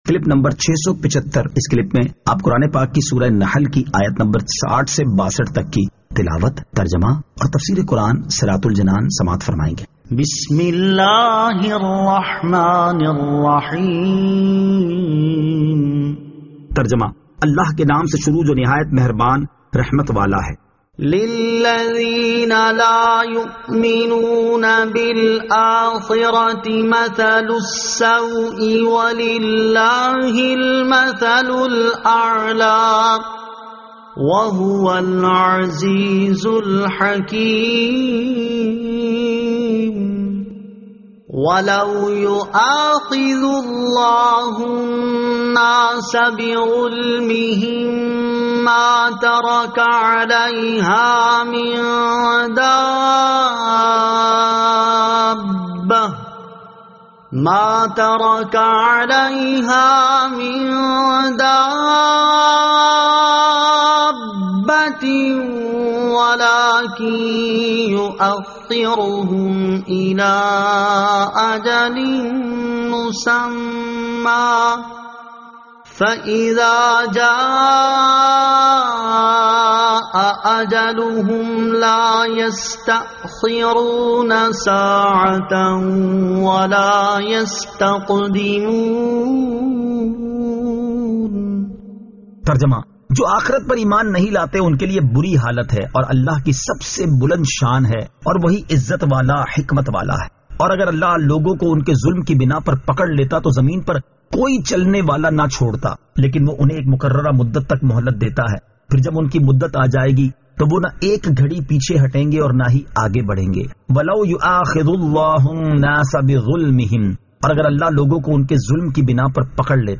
Surah An-Nahl Ayat 60 To 62 Tilawat , Tarjama , Tafseer